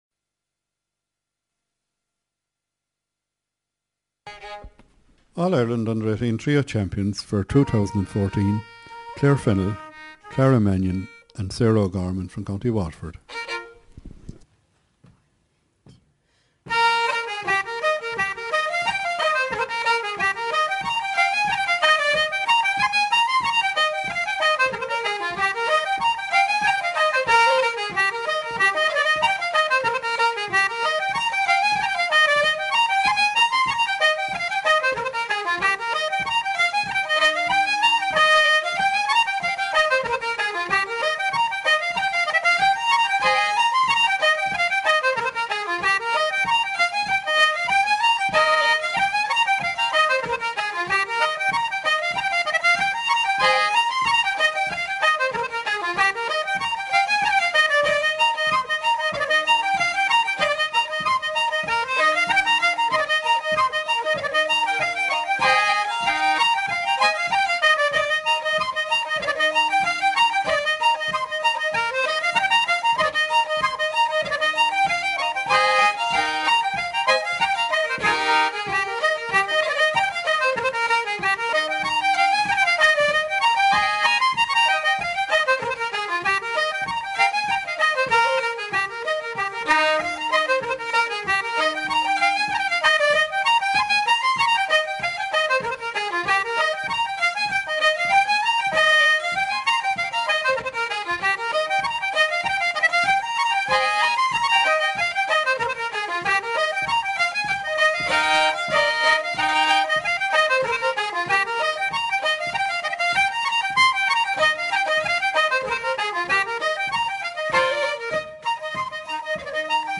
Fleadh, Competition, Trio, Flute, Concertina, Fiddle, Reel, ComhaltasLive